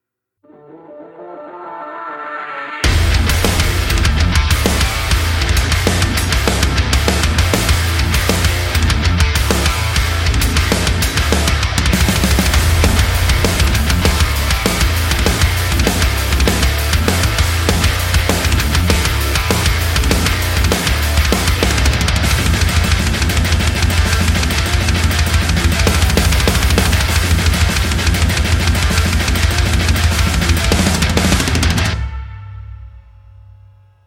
最具侵略性的鼓库